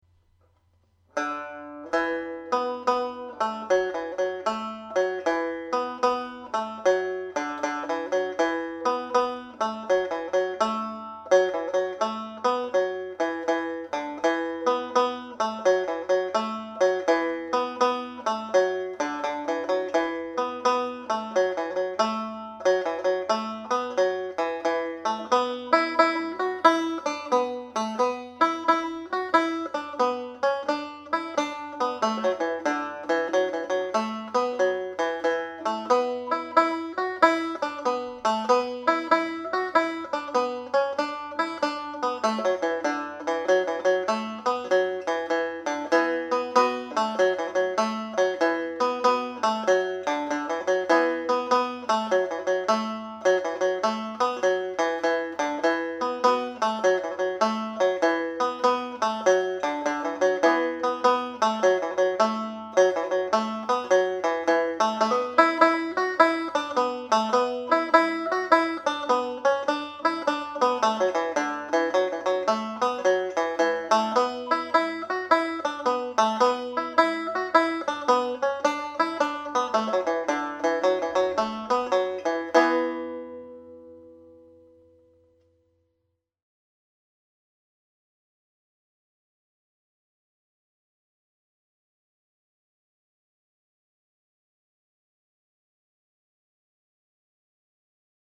Slide (E Minor)
played at slide speed